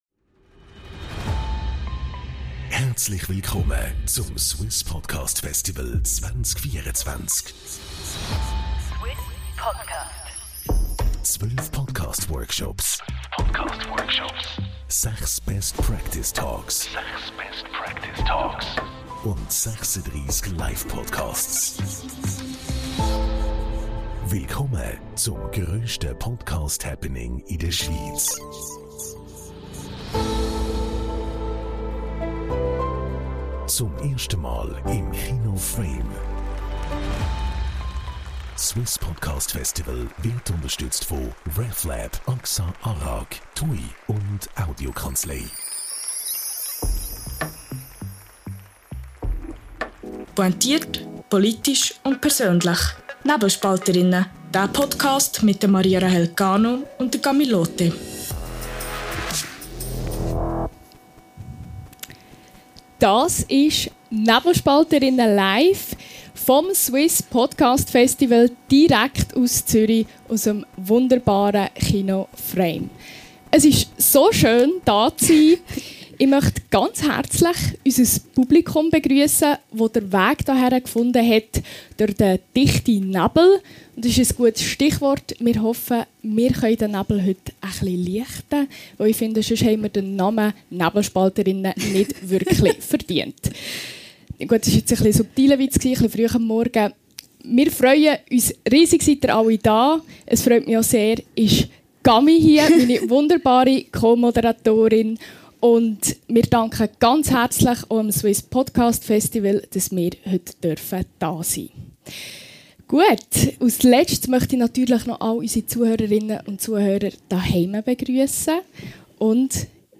Die Nebelspalterinnen traten live beim Suisse Podcast Festival 2024 in Zürich auf. In gelbe Kinosessel gekuschelt und mit einem Glas französischem Rosé in der Hand ging es bei ihrem ersten Live-Auftritt ordentlich zur Sache.